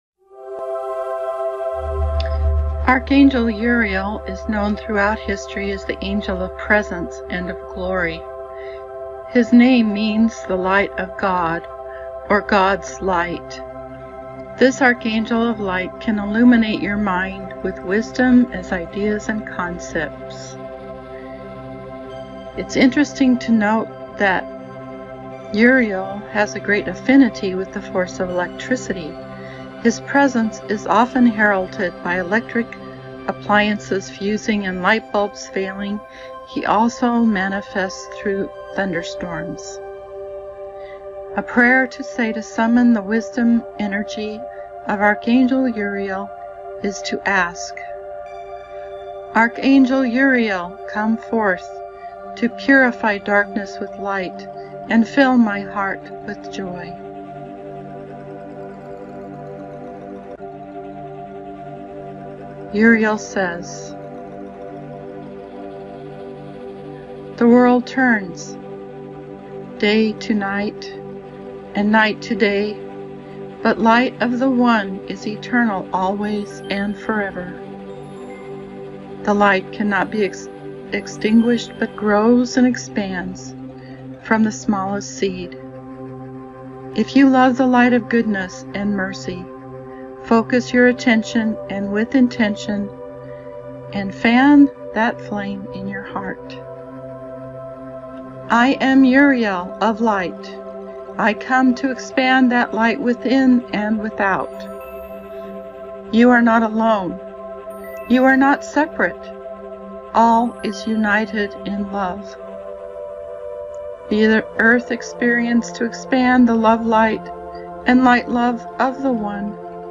Below is a powerful angelic meditation in which the earth is sealed in a grid of electrical light energy by Archangel Uriel.